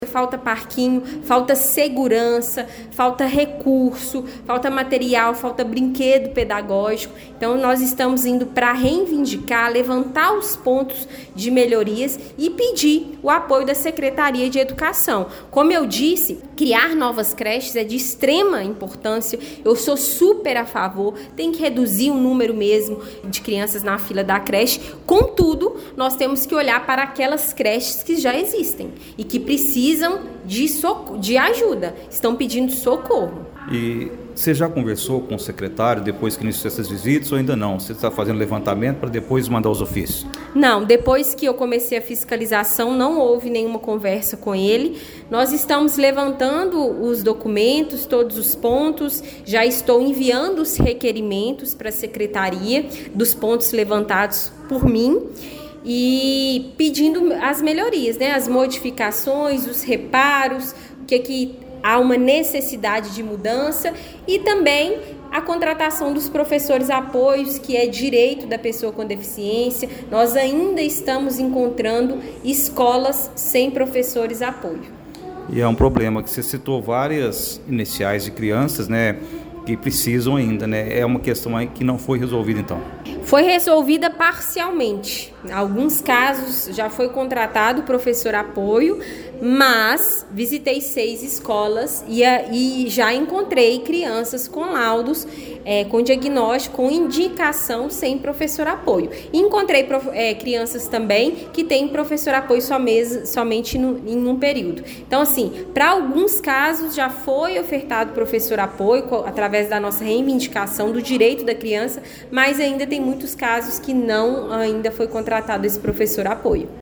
A qualidade do ensino e a infraestrutura das instituições municipais de Pará de Minas foram o centro das atenções durante reunião da Câmara Municipal, realizada ontem (07).